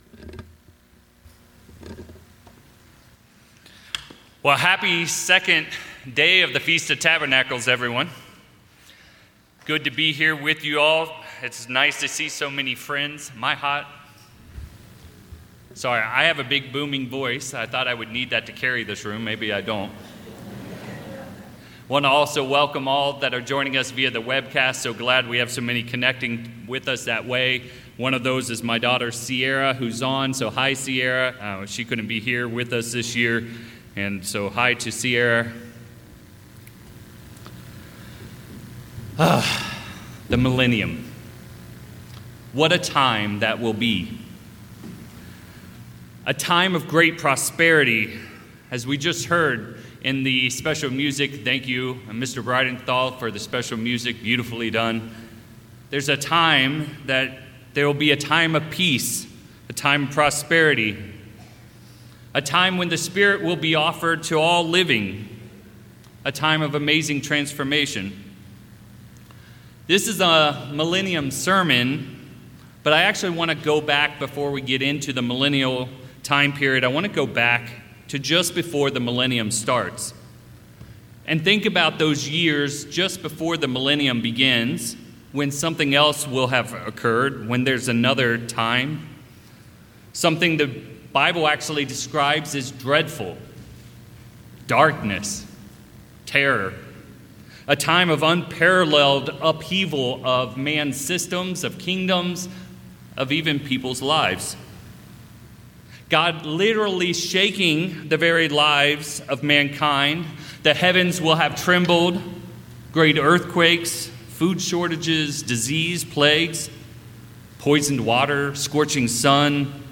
People living at the beginning of the Millennium will be suffering and struggling, as God, Jesus Christ and God’s children (us) begin working with those who are broken emotionally and physically. It is from brokenness that God can often reach human hearts. In this sermon, we will consider many biblical people that God met in their brokenness, where they allowed God to work in their lives such as David, Ruth, Job, and others.